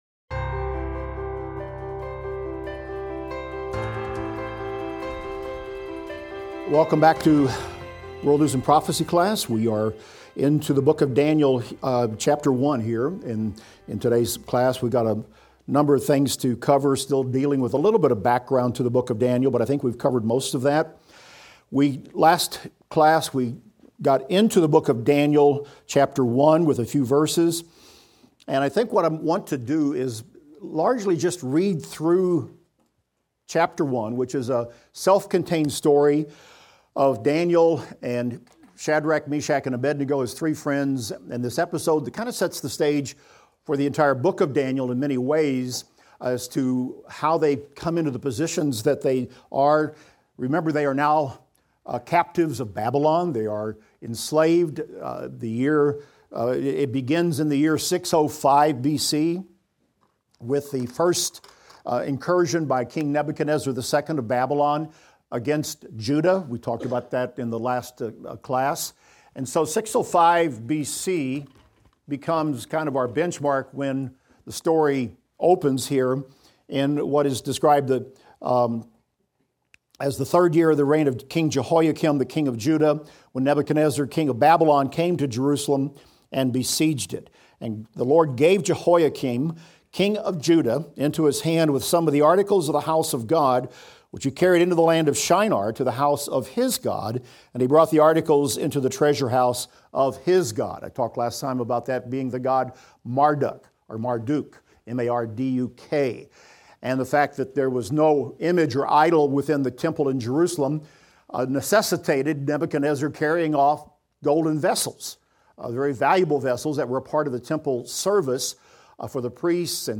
Daniel - Lecture 3 - audio_1.mp3